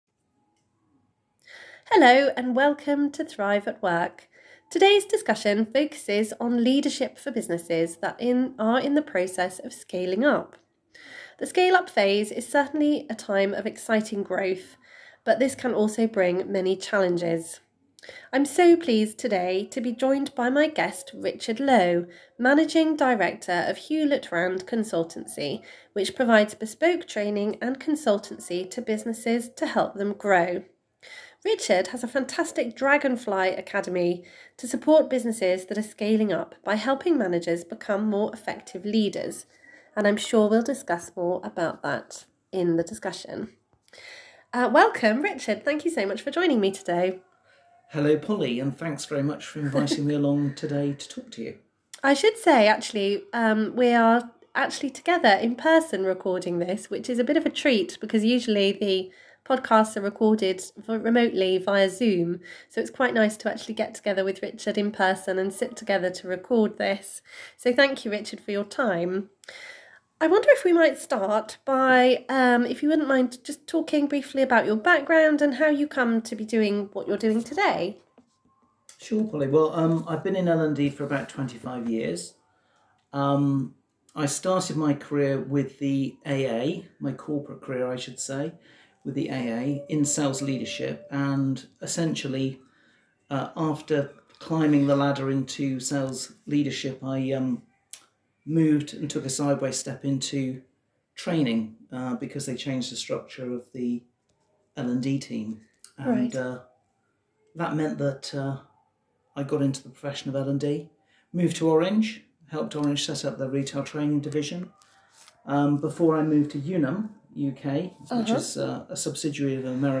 Listen to our Interview In this interview